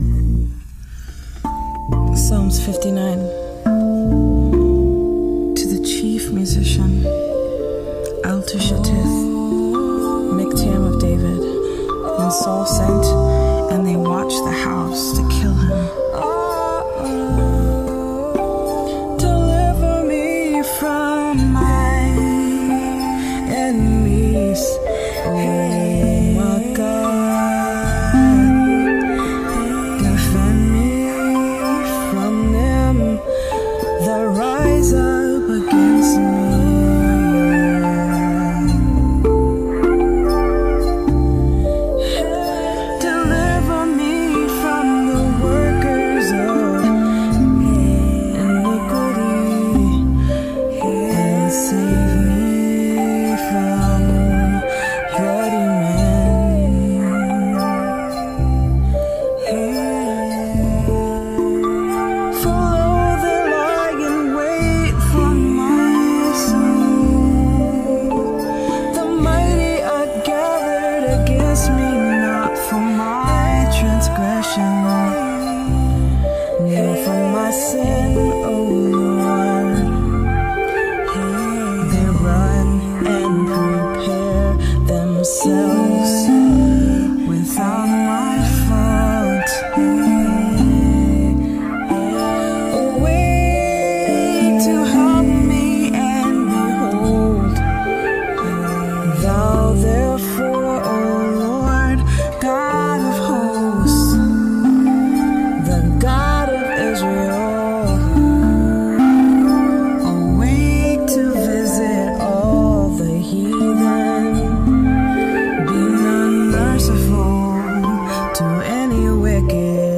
Rav vast drum
tongue drum